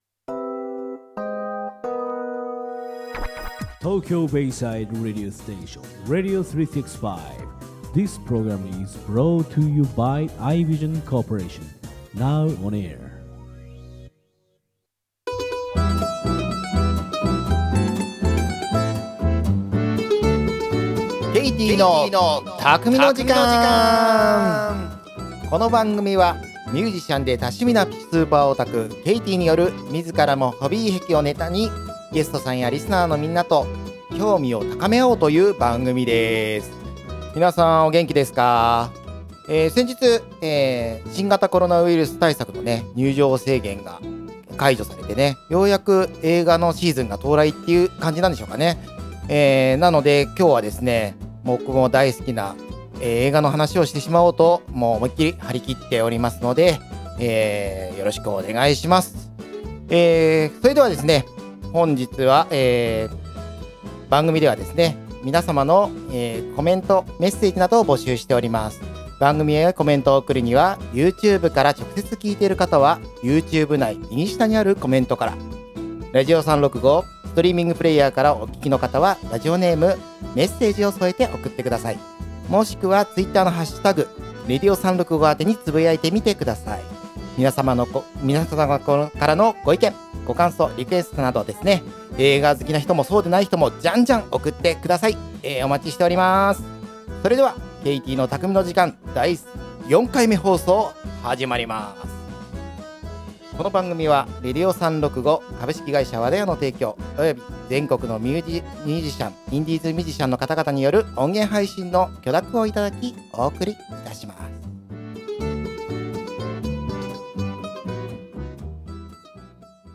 今日は、もちろん僕も大好きな映画の話をしてしまおうと！張り切っております。 【この音源は生放送のアーカイブ音源となります】